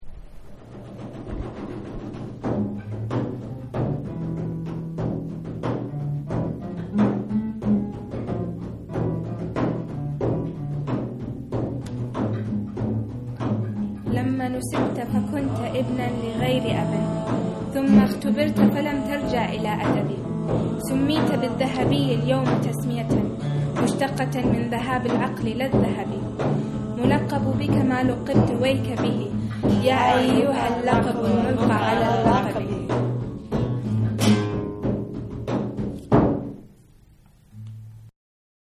Our most multilingual poem-recording session ever. Unmastered, tracks just thrown together–but still sounding awesome.